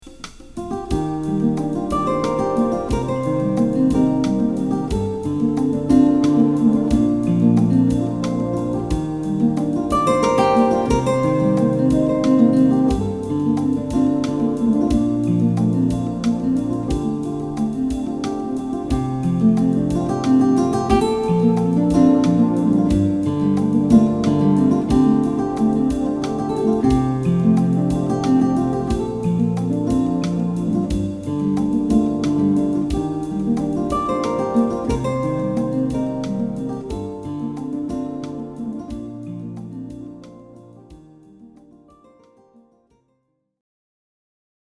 Kirtan & Devotional Music
yogic devotional song ‘OM Shanti